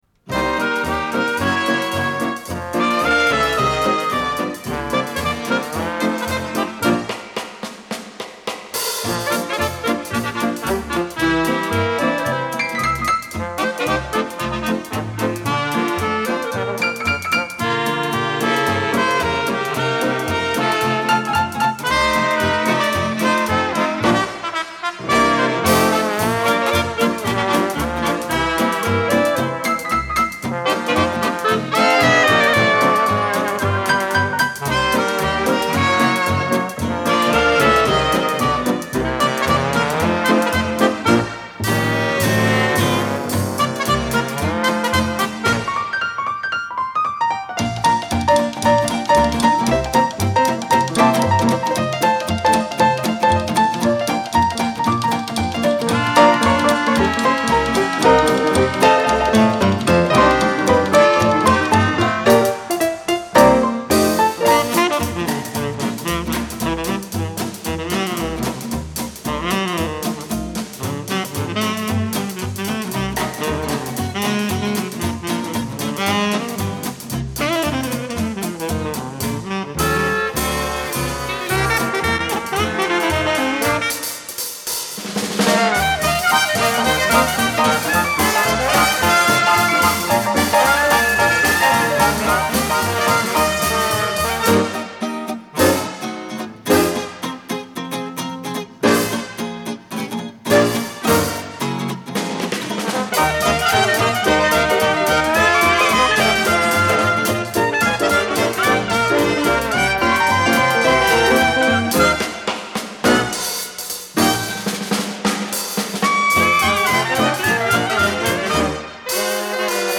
саксофон
тромбон
труба
кларнет
ударные
гитара